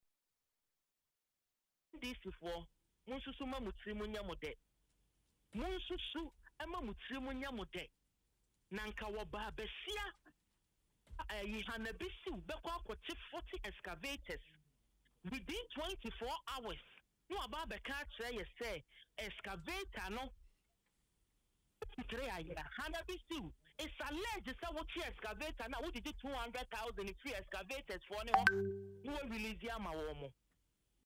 Speaking on Adom FM’s Dwaso Nsem, MP Gloria Owusu questioned the credibility of that account, claiming that there are widespread allegations suggesting Dr. Bissiw-Kotei facilitates the release of the seized excavators to their owners in exchange for bribes.